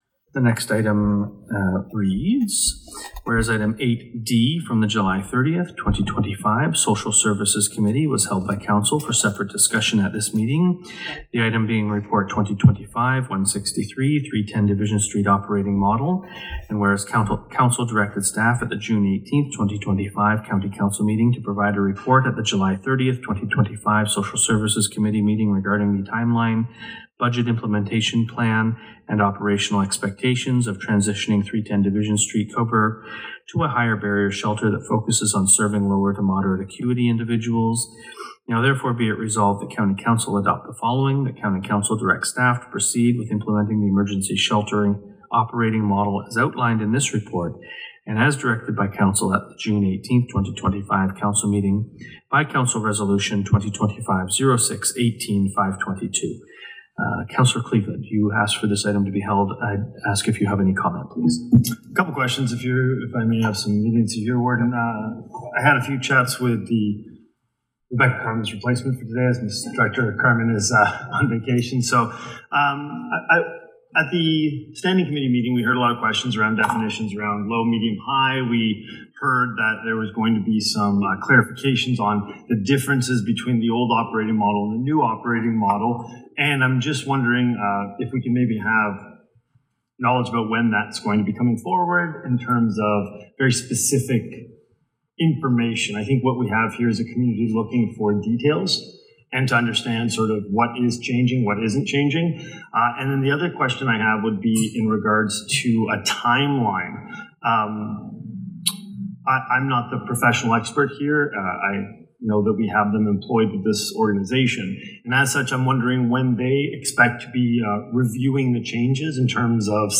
The shelter at 310 Division Street has a new operational model after county council approved details at its Aug. 13 regular council meeting.